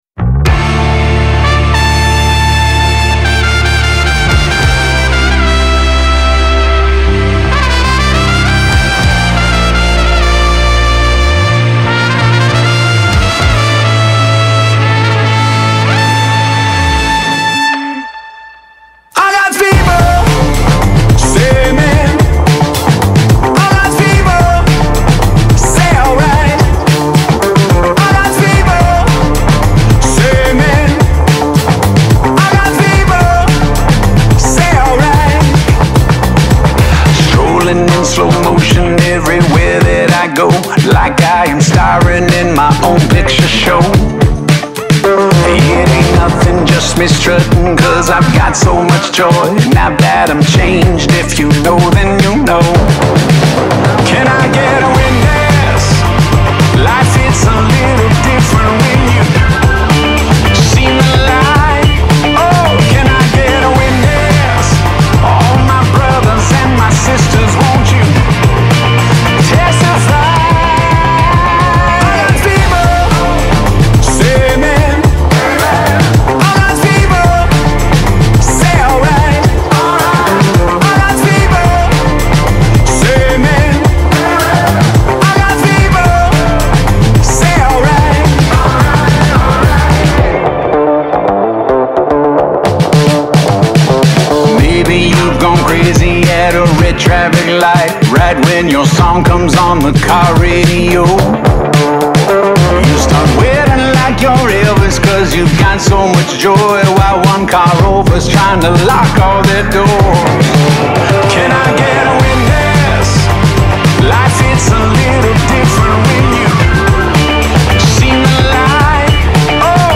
песня
97 просмотров 123 прослушивания 6 скачиваний BPM: 109